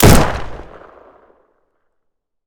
gun_rifle_shot_02.wav